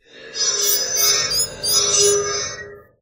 robot_dolphin.ogg